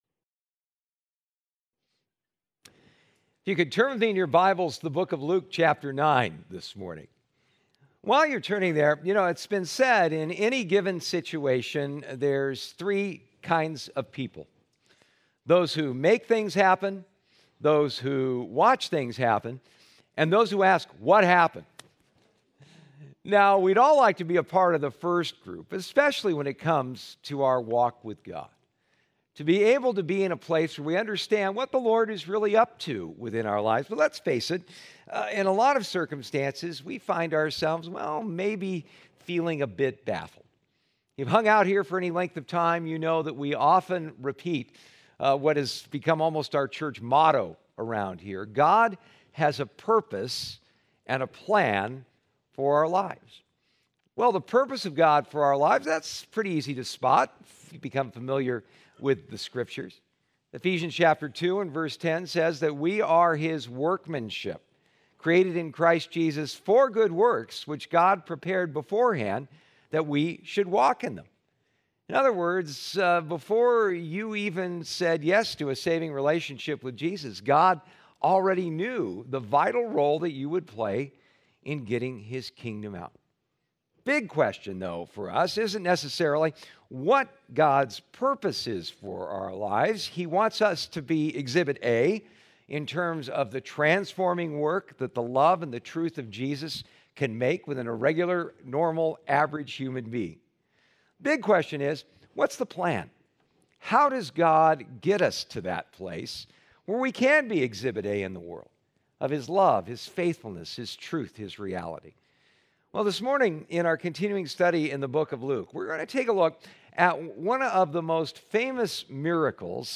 Passage: Luke 9:10-17 Service Type: Sunday Morning